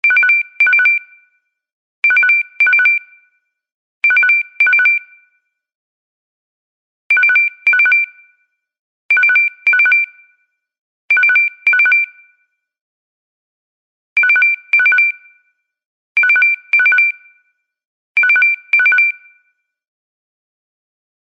lg-simple-bell_24594.mp3